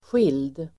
Uttal: [sjil:d]